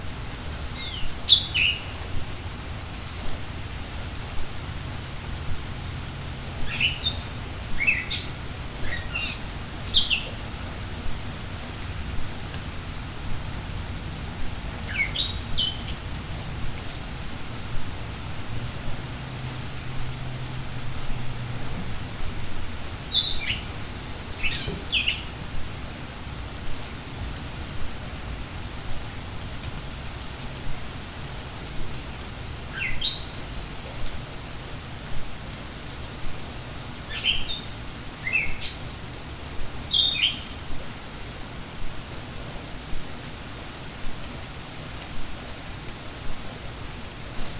جلوه های صوتی
دانلود صدای باران و پرنده در طبیعت از ساعد نیوز با لینک مستقیم و کیفیت بالا